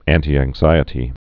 (ăntē-ăng-zīĭ-tē, ăntī-)